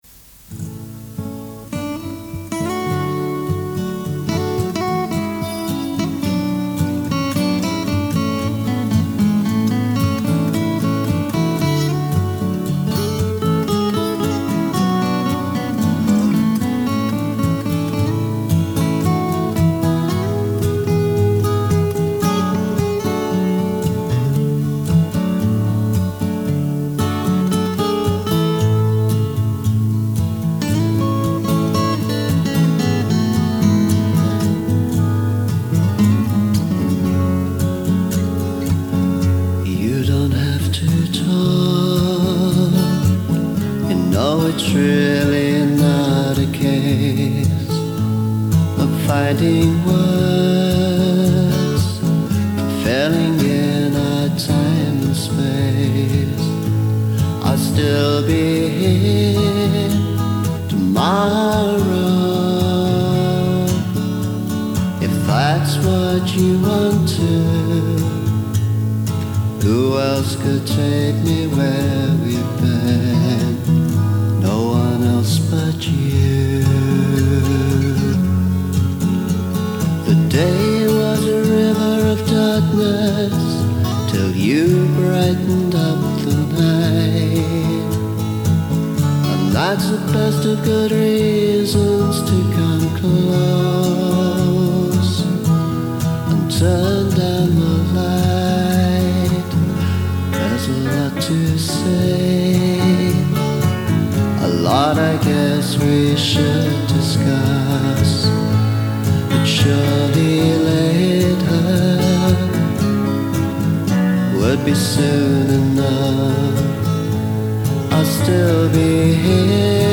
Very soft rock production.
lead and harmony vocals, acoustic lead guitar, electric lead guitars
acoustic guitar, piano
percussion
additional vocals